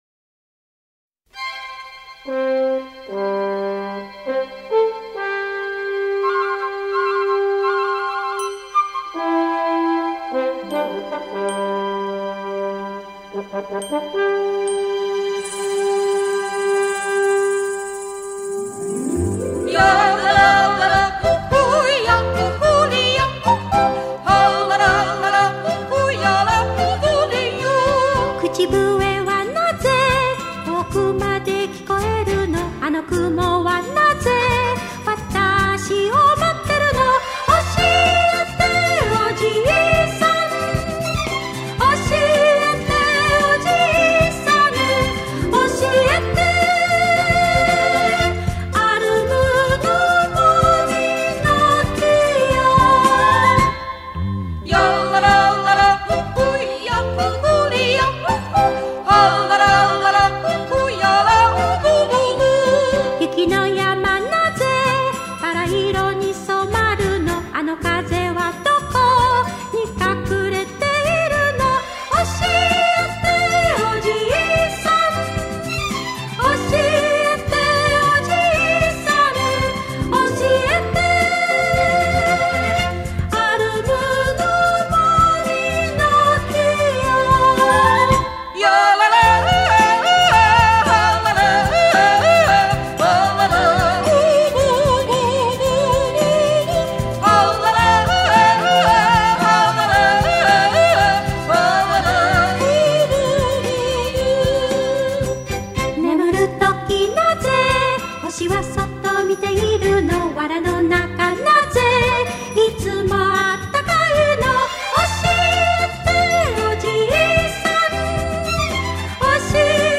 曲の始めに流れるホルンとハープの音色に続き、ヨーデルのコーラスに導かれて始まる。
録音は、日本での録音に、スイスで現地録音したヨーデルとアルペン・ホルンをミックス・ダウンするというものになっている。